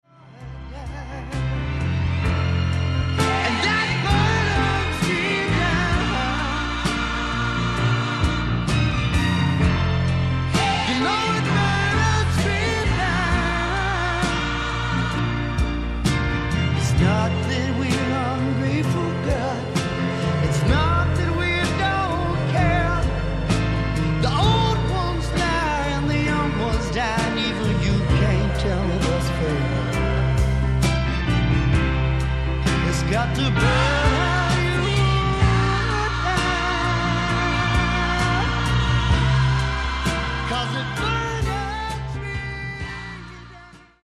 SWAMP ROCK